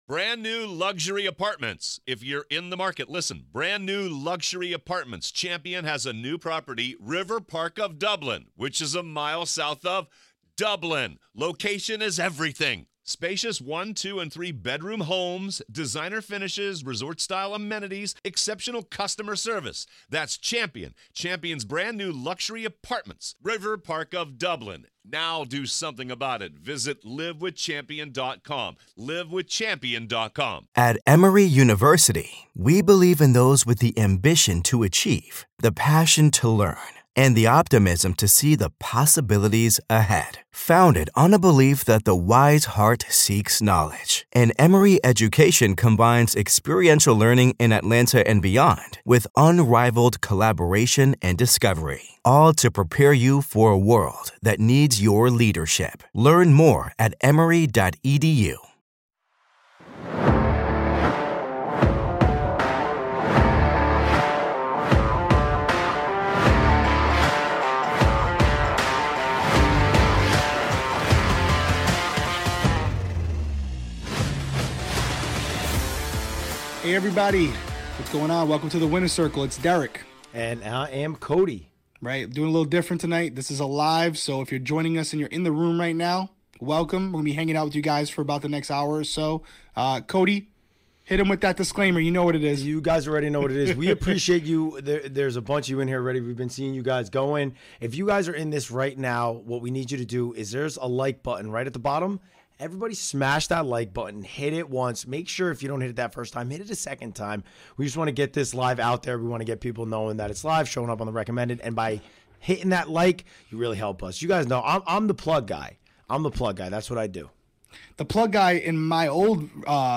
LIVE Q&A | CBS's The Challenge, Pregaming, and More